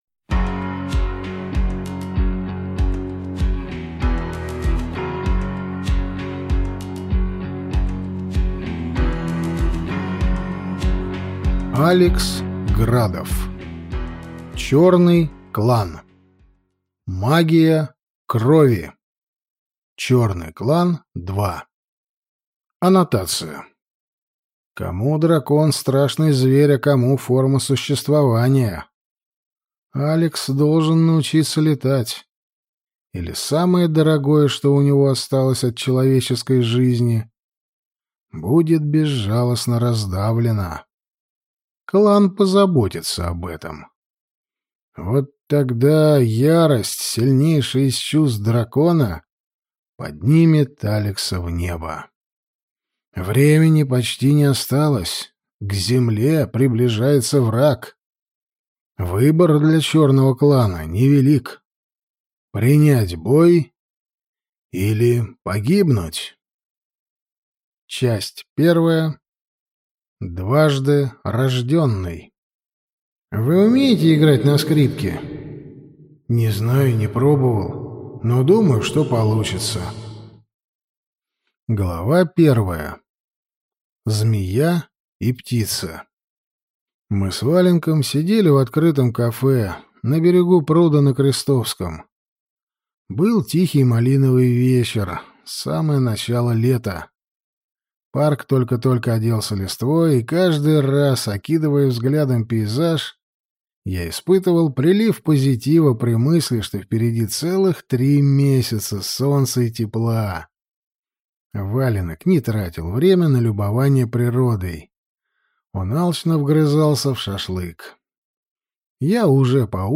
Аудиокнига Черный клан. Магия крови | Библиотека аудиокниг